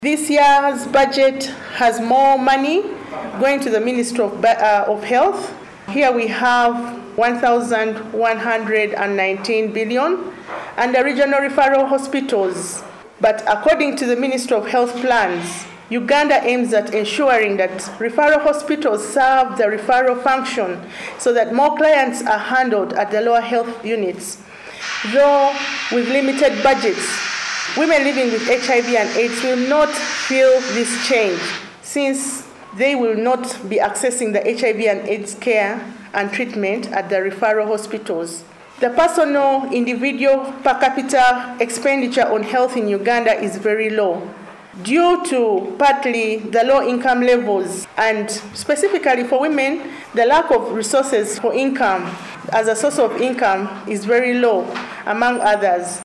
Audio from press conference – International Community of Women living with HIV Eastern Africa